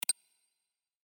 SMS Alert